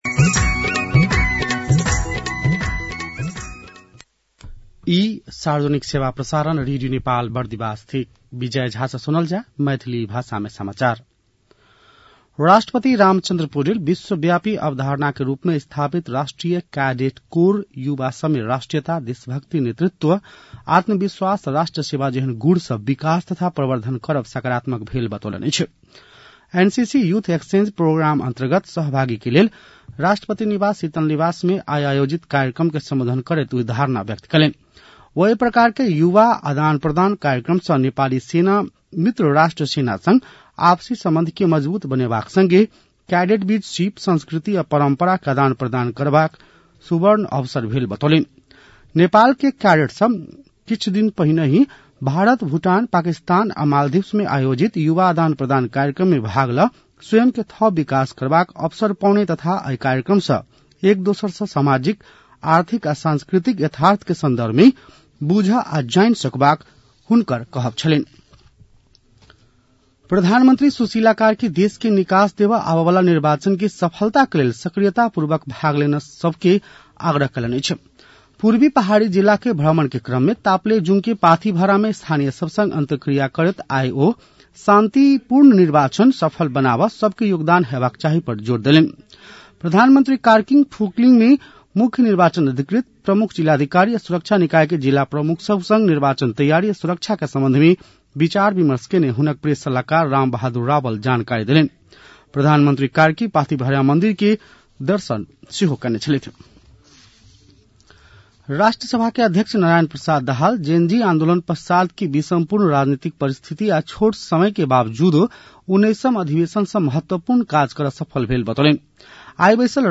मैथिली भाषामा समाचार : १ फागुन , २०८२
6.-pm-maithali-news-1-4.mp3